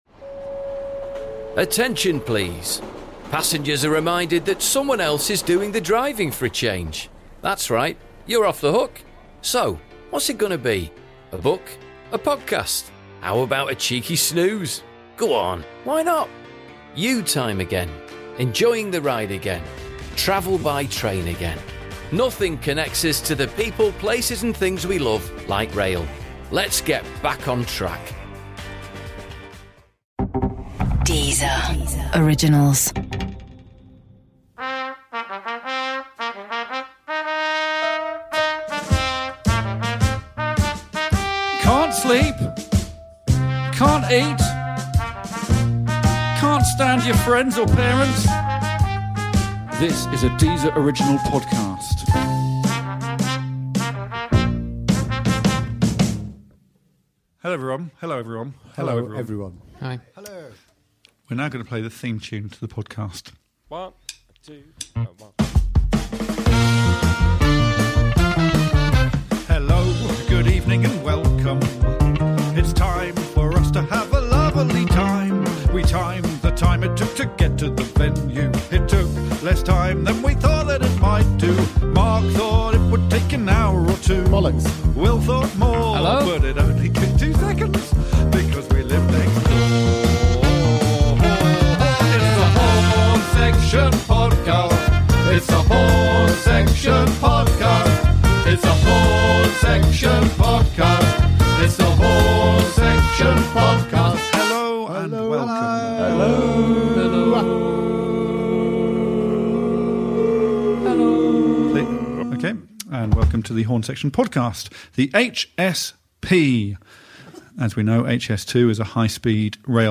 Raucous, ridiculous and surprisingly satisfying - it’s the Horne Section Podcast!
Welcome to The Horne Section Podcast, your new weekly dose of musical nonsense and anarchic chat with Alex Horne and his band! This week we're joined in the studio by comedian and actress Roisin Conaty.